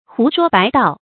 胡說白道 注音： ㄏㄨˊ ㄕㄨㄛ ㄅㄞˊ ㄉㄠˋ 讀音讀法： 意思解釋： 猶言胡說八道。